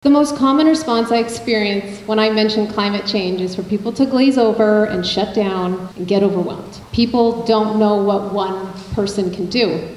The nominees gathered at McNab School September 22nd for an All-Candidate Forum, hosted by the Greater Arnprior Chamber of Commerce.